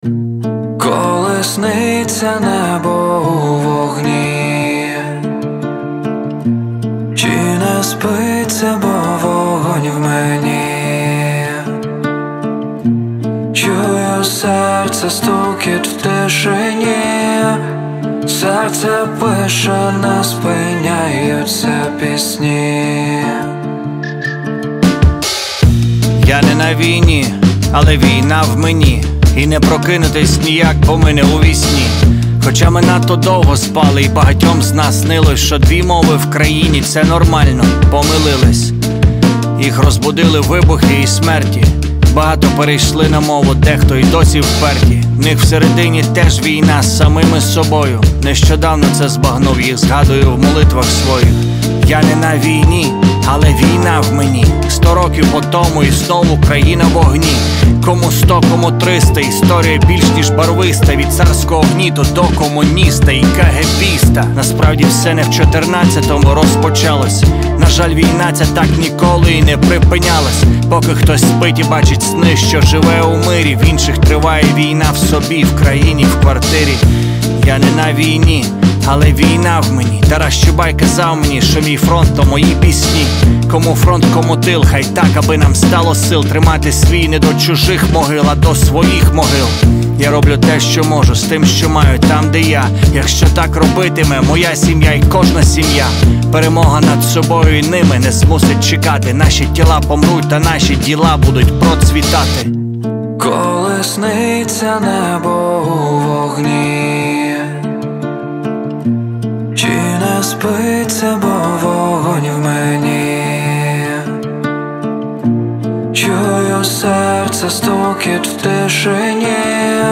• Жанр: Hip-Hop, R&B